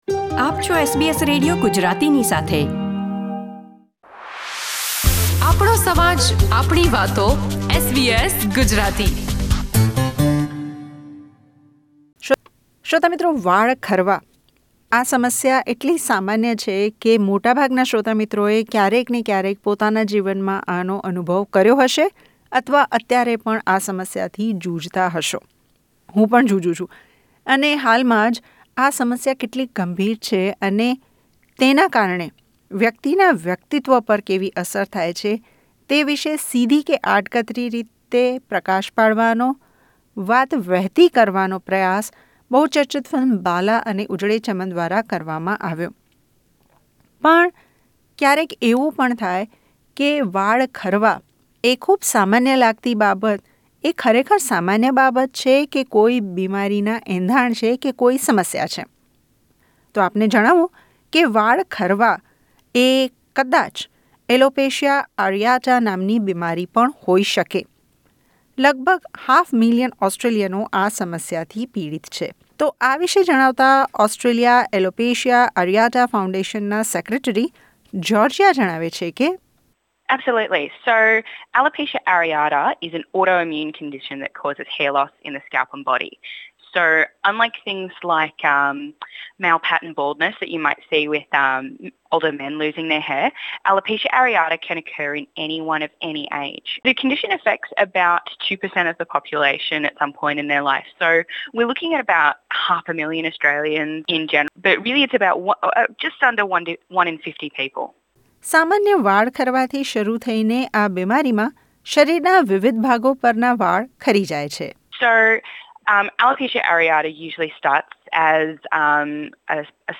આ પોડકાસ્ટને સાંભળો જ્યાં નિષ્ણાતો જણાવે છે કે એલોપેસીયા એરેટા શું છે, માનસિક સ્વાસ્થ્ય પર તેની કેવી અસર પડે છે, અને આયુર્વેદ આ વિશે શું કહે છે?